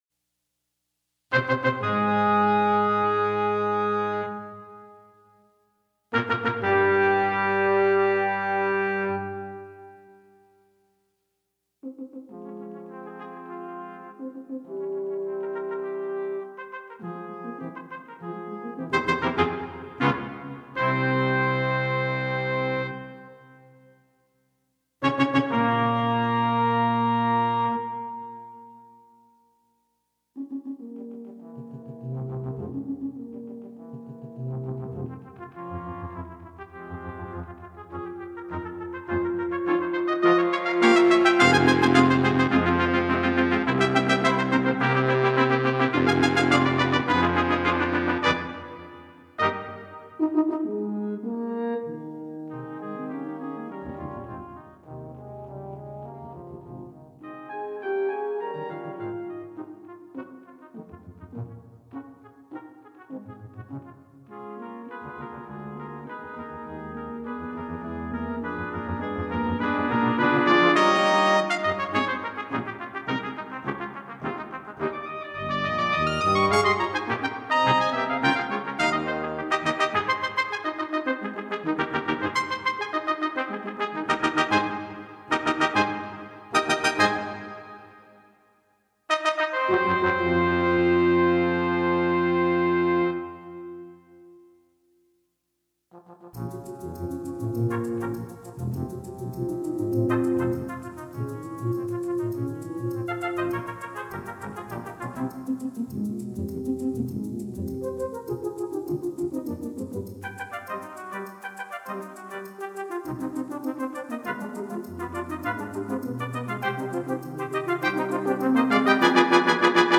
Rousing and strong!